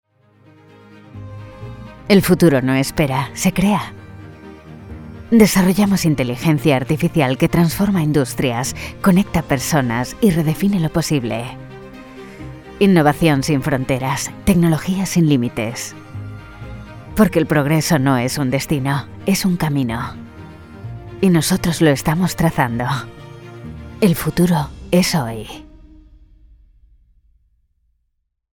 Corporate Videos
Castilian Spanish online voice over artist fluent in English.
Soundproof recording booth ( studiobricks)
Mic Neumann U87 Ai